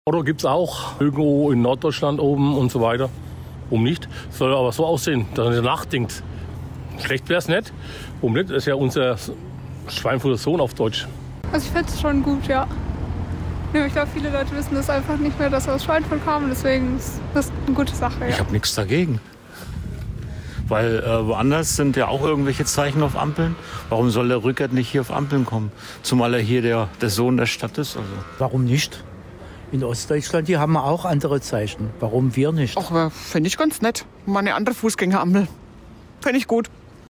Passanten-Umfrage-Meinung-zu-Friedrich-rueckert-auf-den-Ampeln.mp3